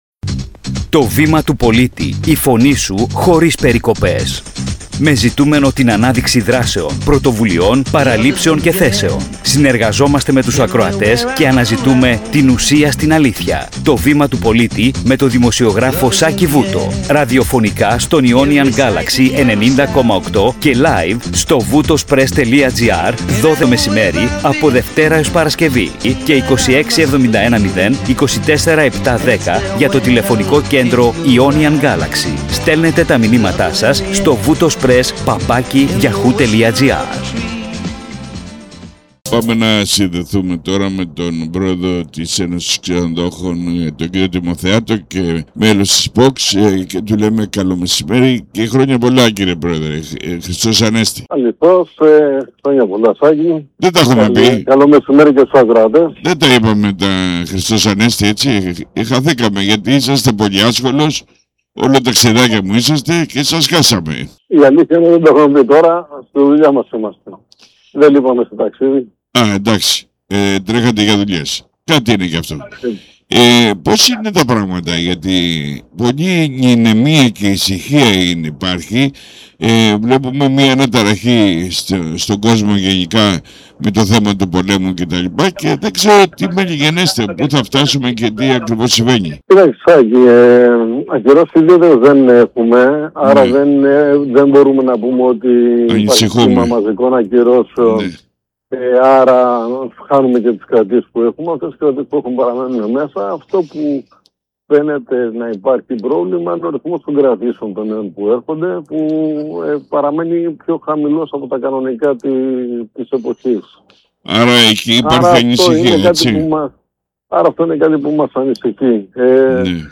Περίληψη συνέντευξης